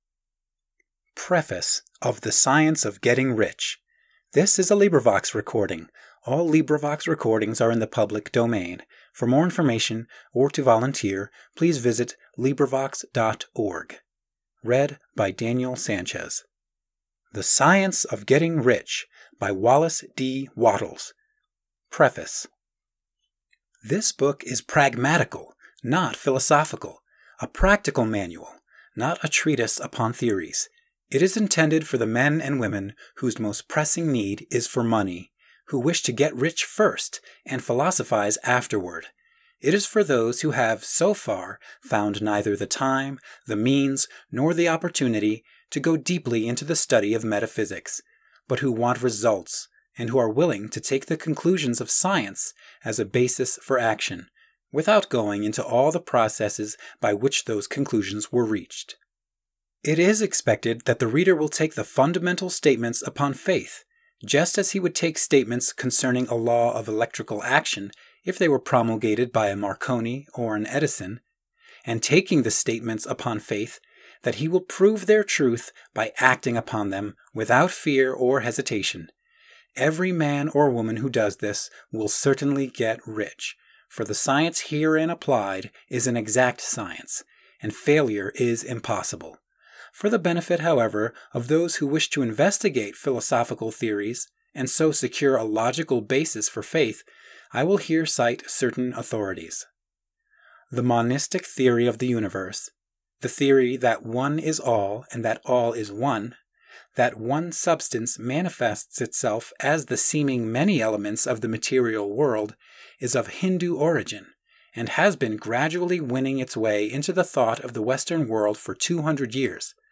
*disclaimer: “This is a LibriVox public domain recording. You are paying for file hosting/convenience, not the content itself.”